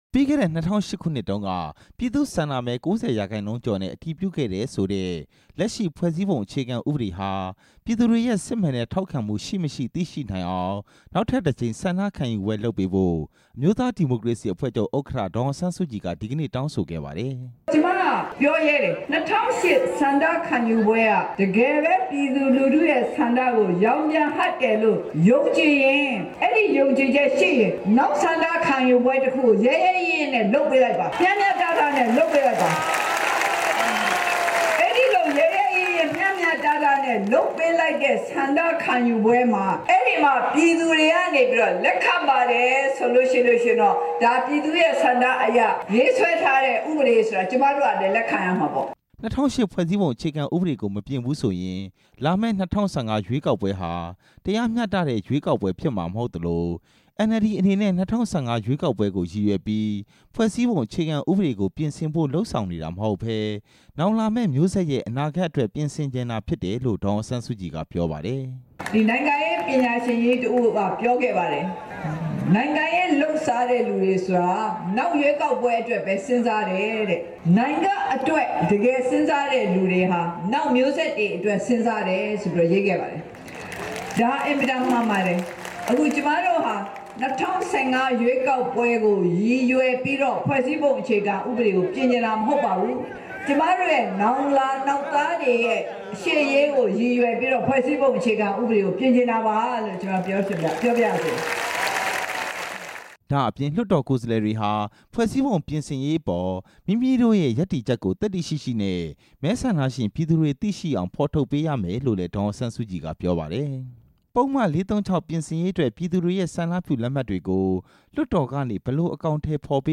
ဒီနေ့ မကွေးတိုင်း ပခုက္ကူမြို့မှာ ကျင်းပခဲ့တဲ့ ပုဒ်မ ၄၃၆ ပြင်ဆင်ရေး လူထုဆန္ဒလက်မှတ်ကောက်ခံပွဲ မှာ ဒေသခံတစ်ဦးက ယူနတီဂျာနယ်က သတင်းထောက်တွေကို ထောင်ဒဏ် ၁၀ နှစ်ချခံ ရတာနဲ့ပတ်သက်ပြီး မေးမြန်းရာမှာ ဒေါ်အောင်ဆန်း စုကြည်က အခုလို ဖြေကြားခဲ့တာပါ။